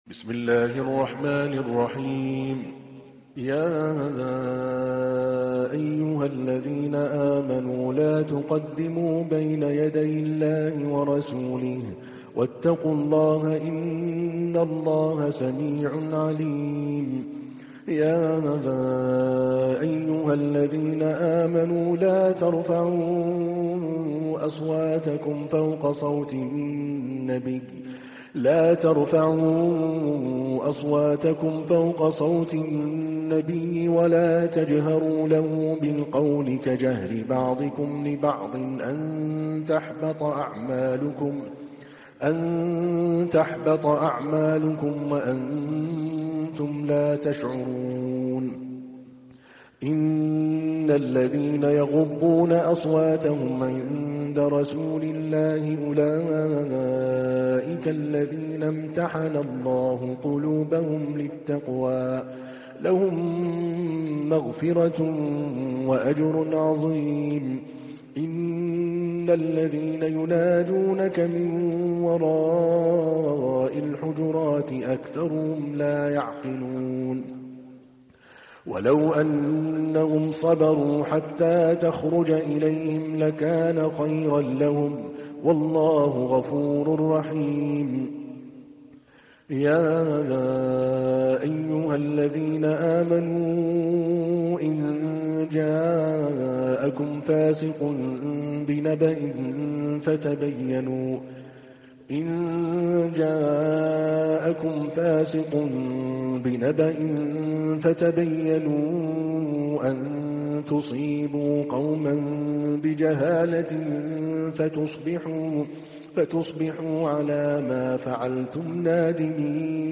تحميل : 49. سورة الحجرات / القارئ عادل الكلباني / القرآن الكريم / موقع يا حسين